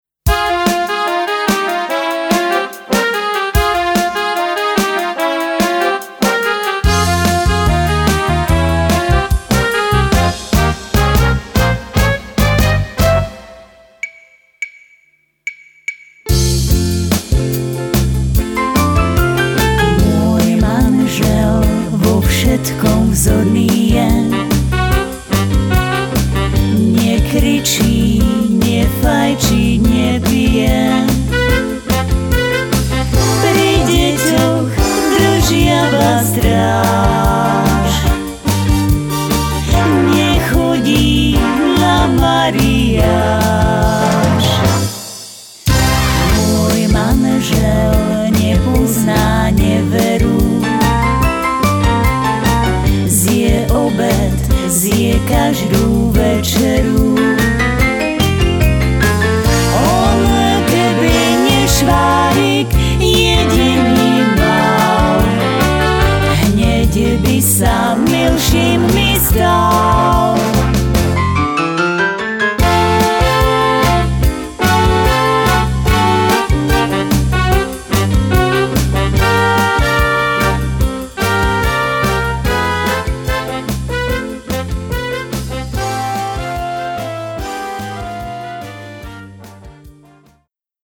obsahuje duety